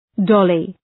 Shkrimi fonetik {‘dɒlı}